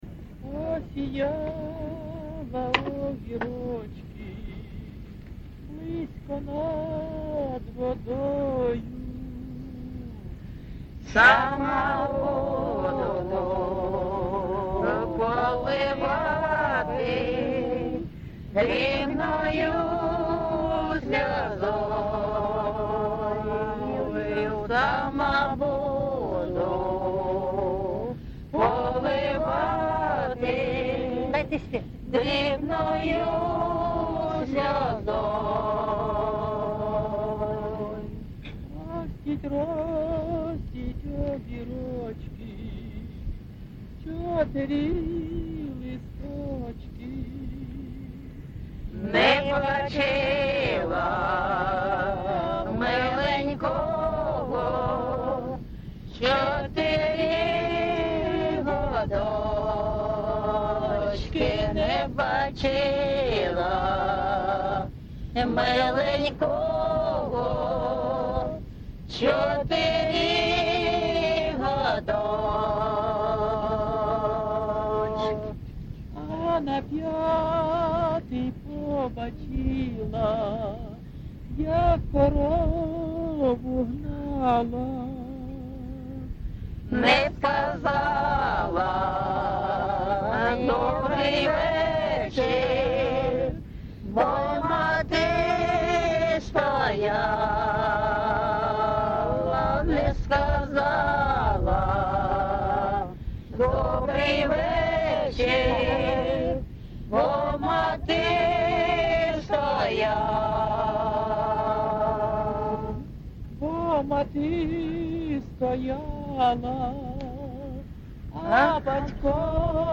ЖанрПісні з особистого та родинного життя
Місце записус. Богородичне, Словʼянський район, Донецька обл., Україна, Слобожанщина